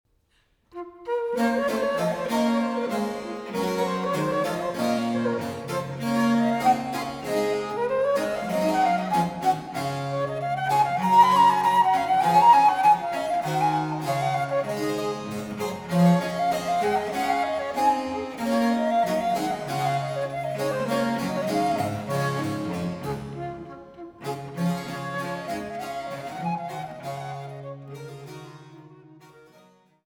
Sinfonie à Flûte traverse seule
Tendrement